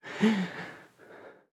Player_UI [34].wav